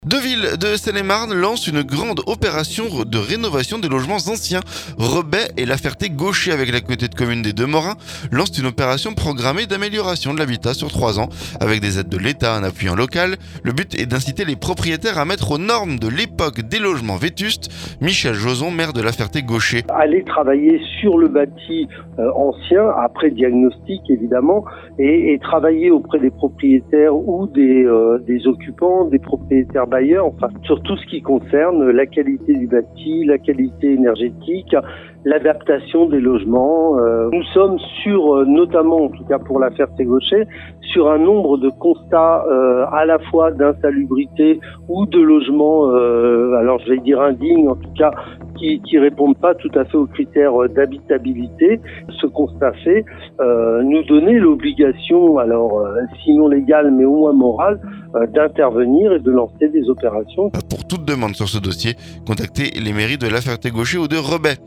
Michel Jozon, maire de La-Ferté-Gaucher.